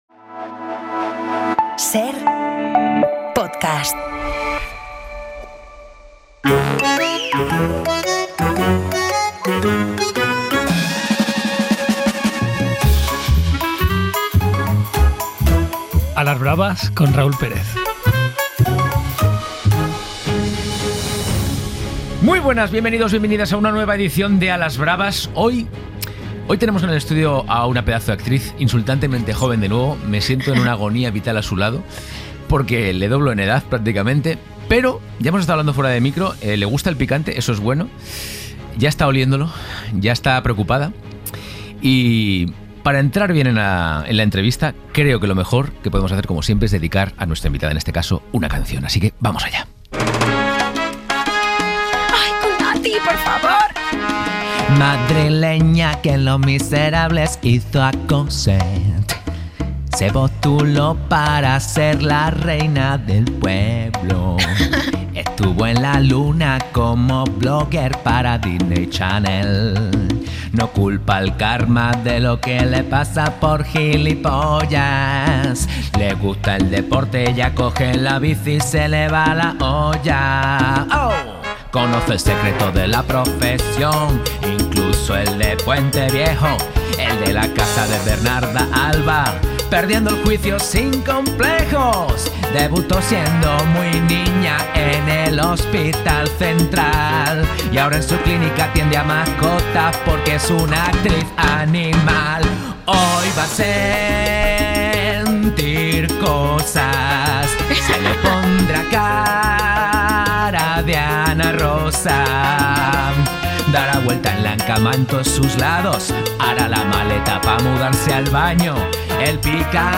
De Disney Channel a los escenarios con 'Los Miserables', pasando por varias series y películas hasta llegar a 'Animal' en Netflix, junto a Luis Zahera. Lucía Caraballo se pasa por A las Bravas en un programa cargado de acento gallego, imitaciones, mucho picante y momentos que ya son historia del programa.